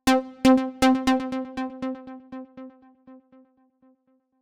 Synth Sound
Synth Sound_0.ogg